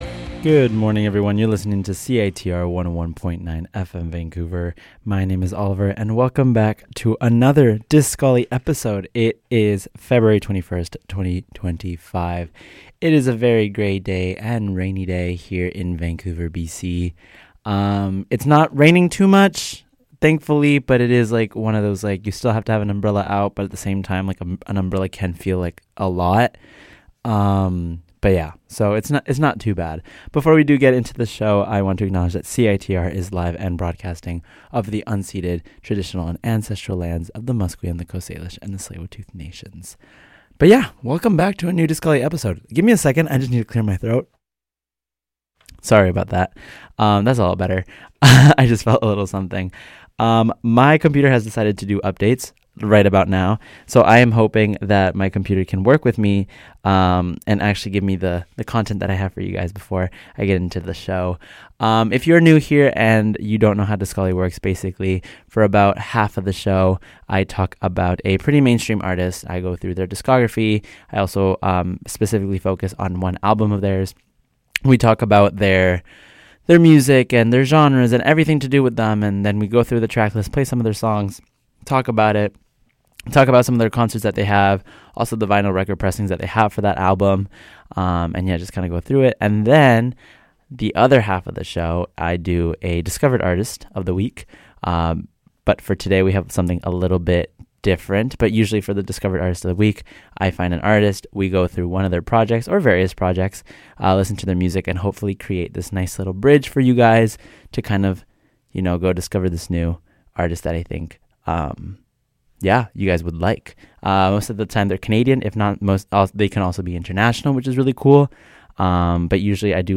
Based in Mexico City, Petite Amie is a Psychedelic indie pop-rock band that experiments with genre fluidity and singing in both French and Spanish.